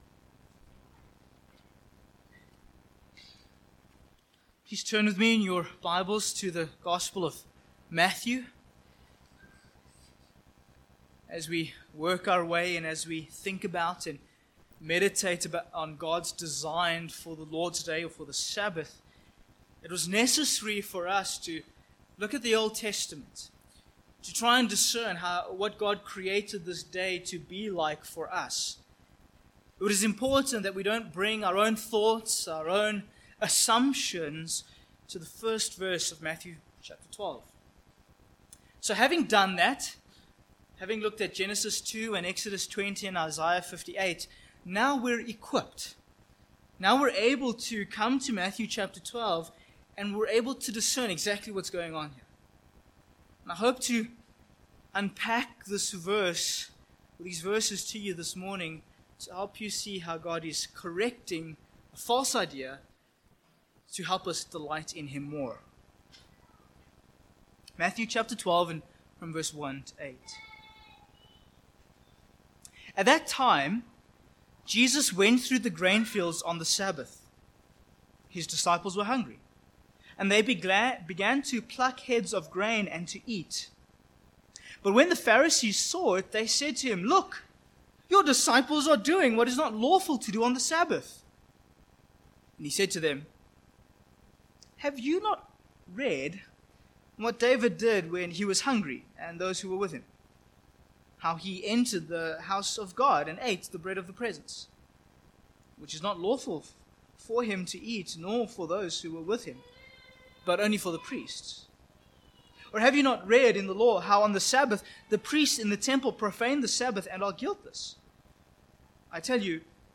The Lord's Day Passage: Matthew 12:1-8 Service Type: Morning « The Lord’s Day In The Kingdom The Lord’s Day In Christ